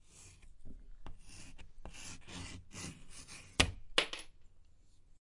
折断
描述：啪的一声骨裂了
Tag: 断裂 裂缝 卡扣